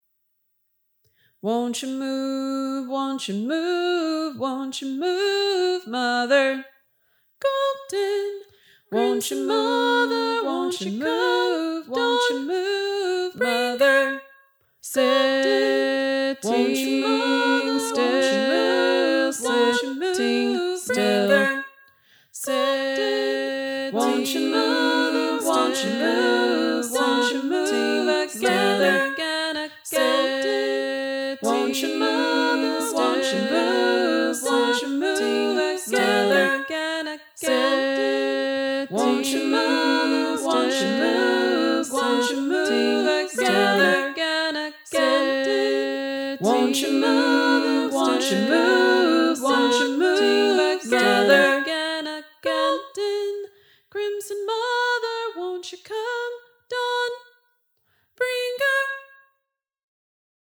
Here are the words to this simple but powerful chant:
chant